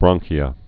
(brŏngkē-ə)